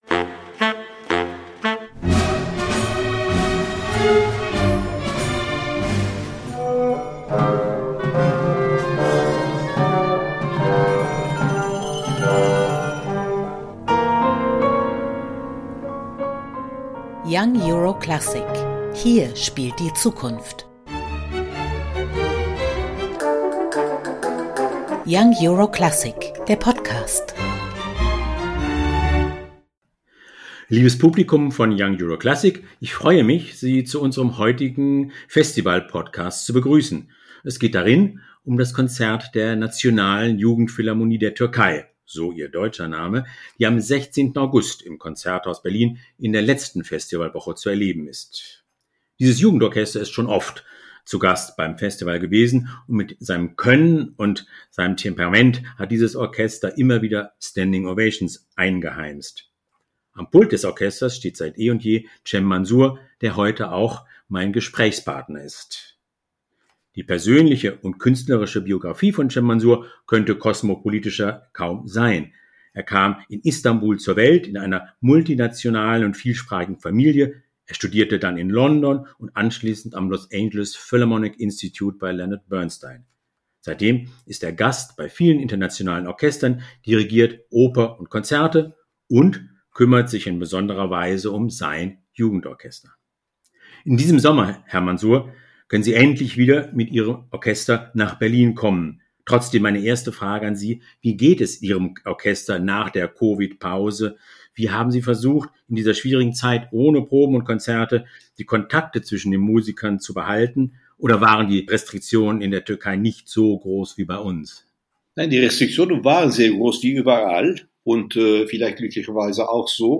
Beschreibung vor 3 Jahren Konzerteinführung 16.08.2022 | Gibt es noch Hoffnung für die Menschheit?